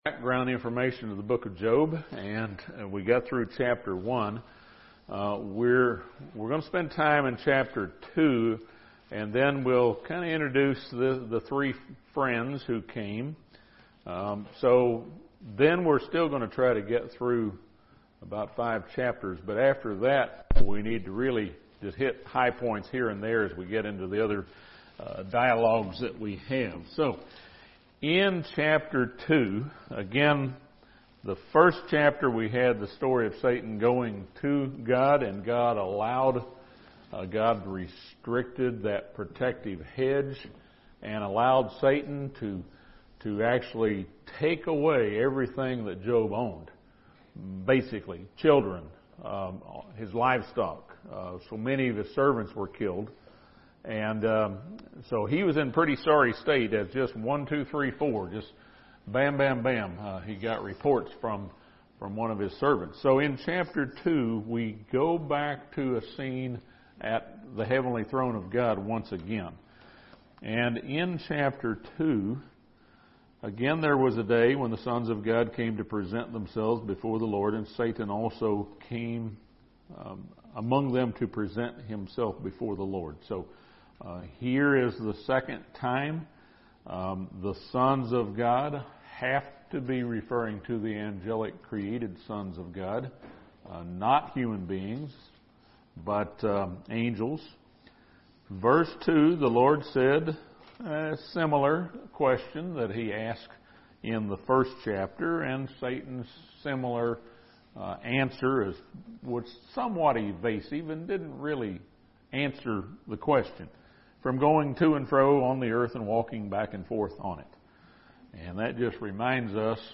This Bible Study discusses the story from Job chapters 2-7.